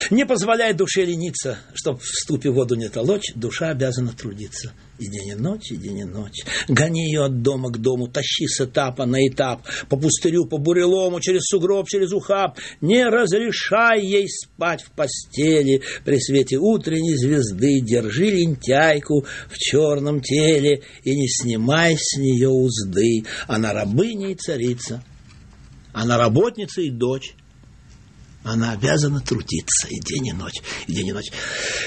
16. «читает Александр Филипенко – Николай Алексеевич Заболоцкий (1903 – 1958) – Не позволяй душе лениться (читает Александр Филипенко)» /